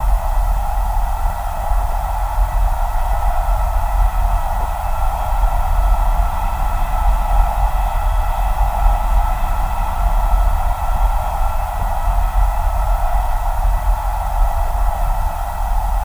Torpedos
Torpedo Jet By